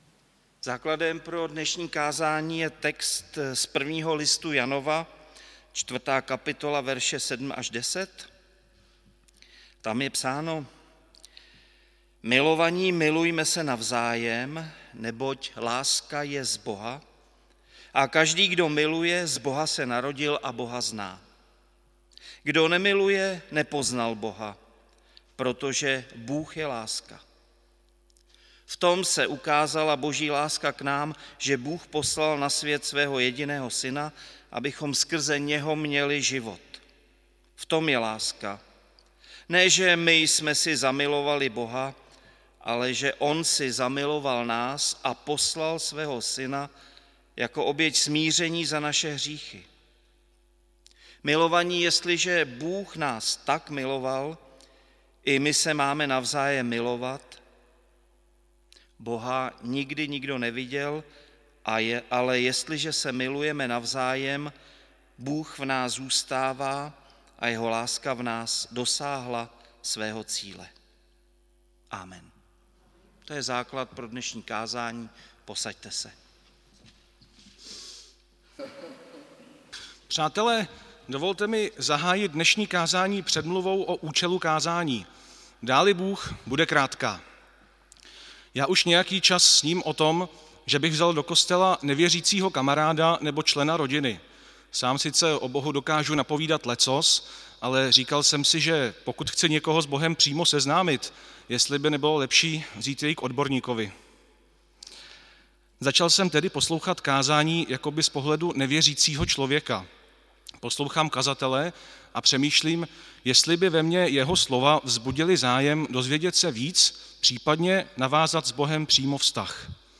Bohoslužby s VP 7. 4. 2024 • Farní sbor ČCE Plzeň - západní sbor